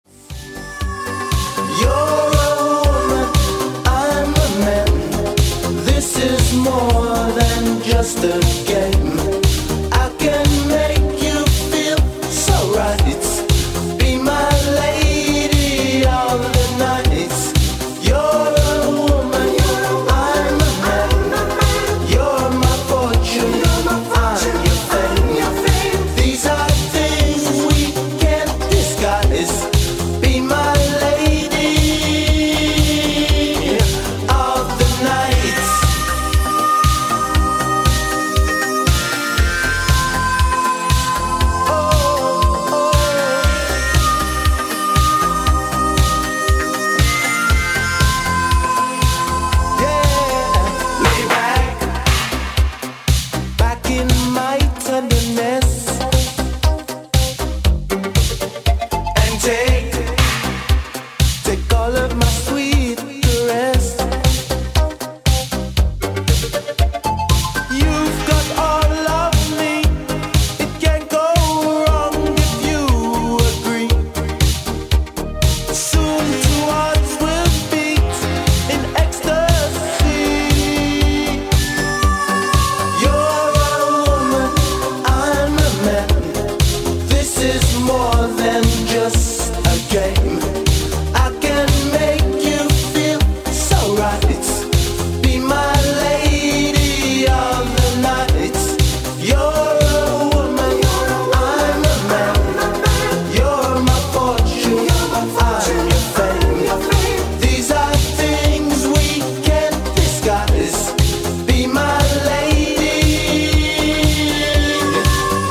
• Качество: 128, Stereo
disco
80-е
europop